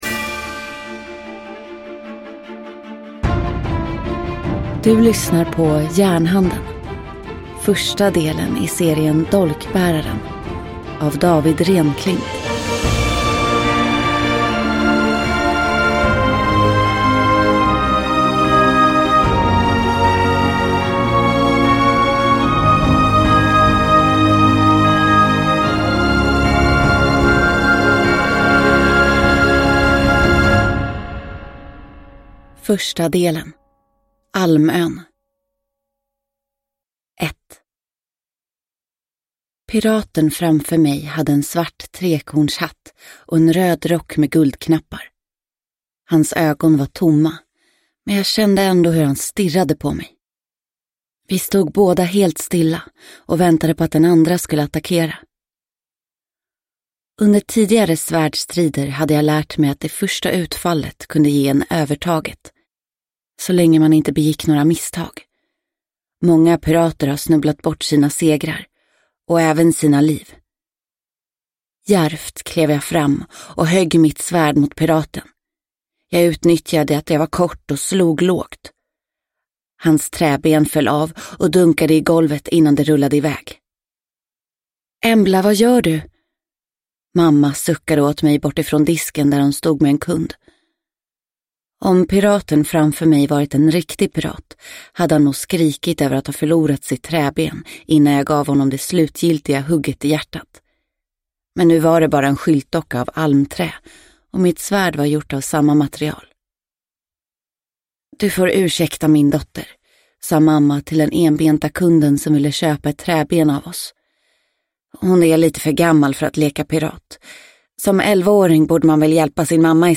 Järnhanden – Ljudbok – Laddas ner